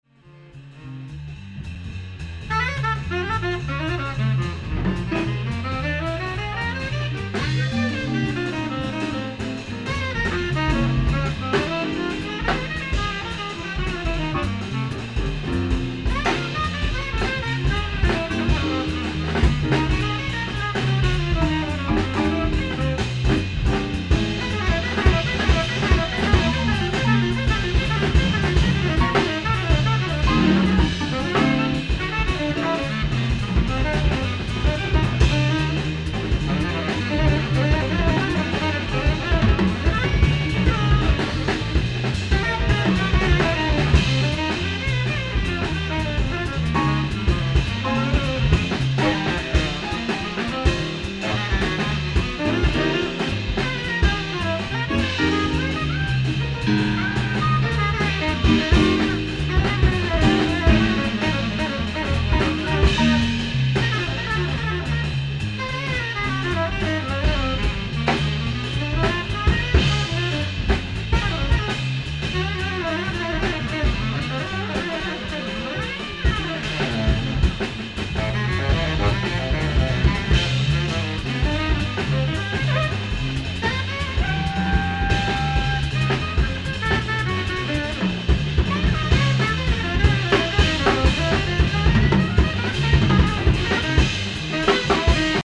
ライブ・アット・ブルーノート、ニューヨーク 01/03/1993